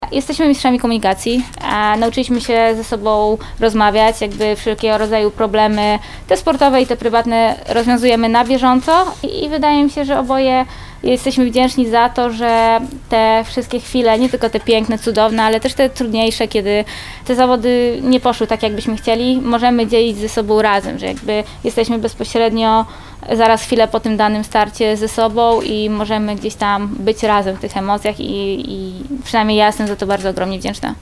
Mistrzyni olimpijska w Lublinie – konferencja prasowa z Aleksandrą Mirosław